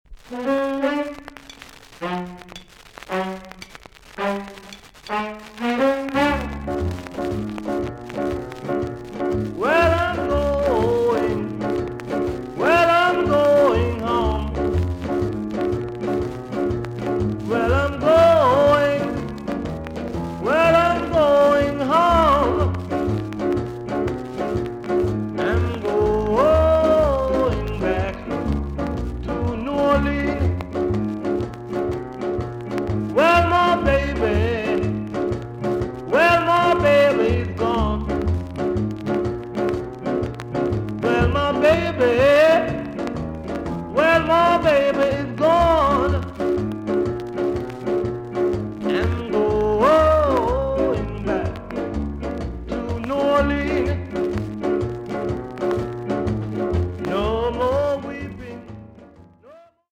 TOP >SKA & ROCKSTEADY
VG ok 全体的に軽いチリノイズが入ります。